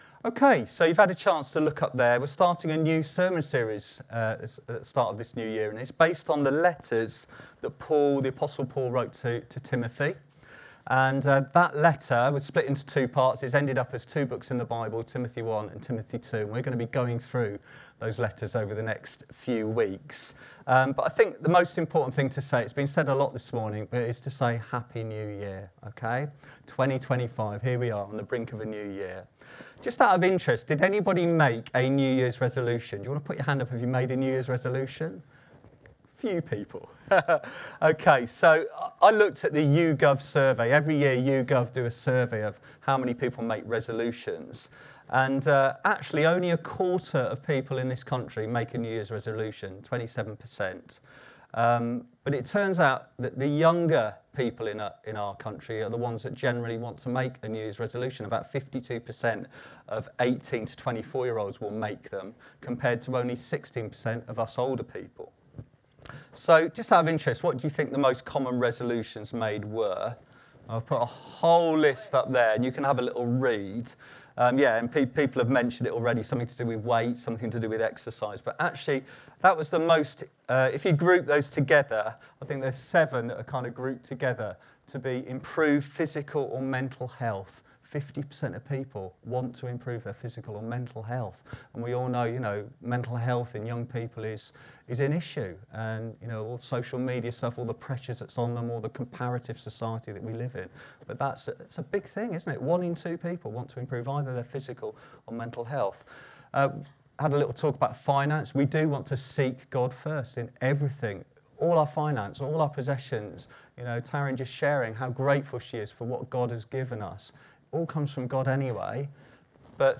Download Opposing false teaching | Sermons at Trinity Church